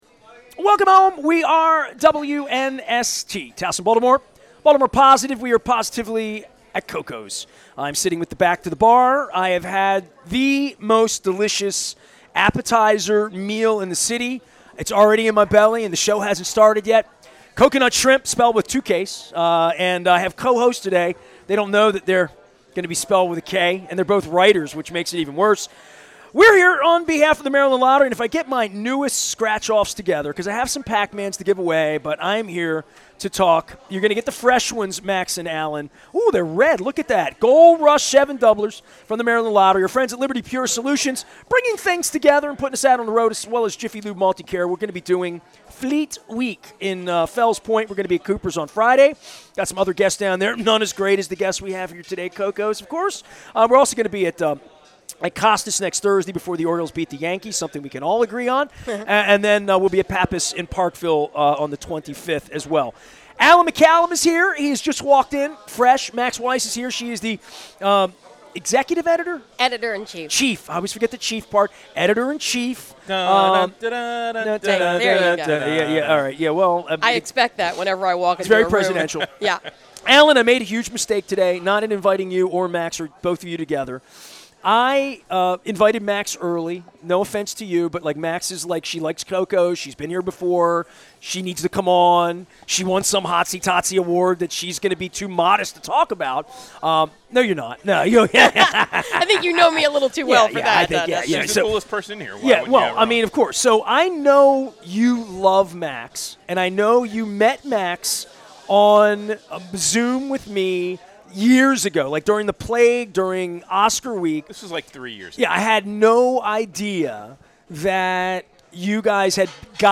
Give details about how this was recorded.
on Crab Cake Tour